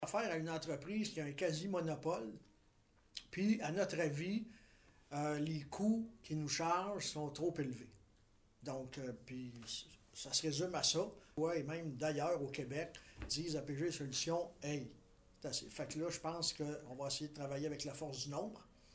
Le maire de Bromont, Louis Villeneuve :